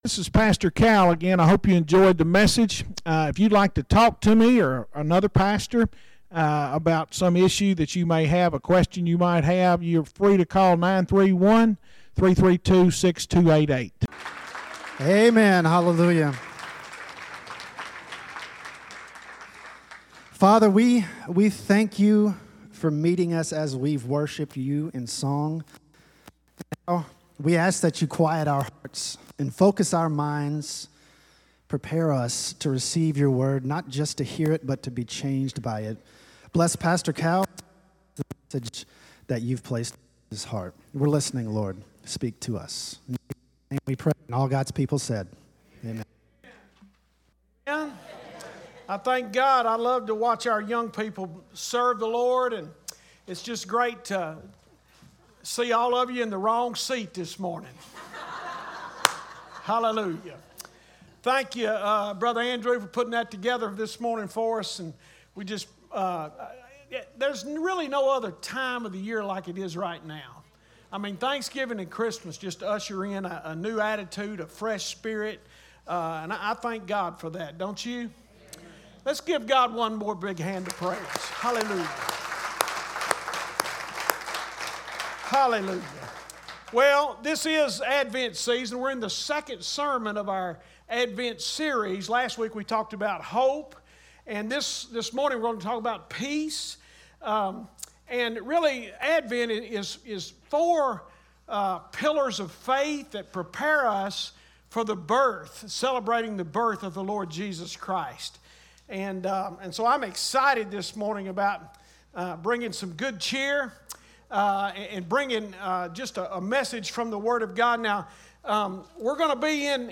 SERMONS - 2nd Mile Church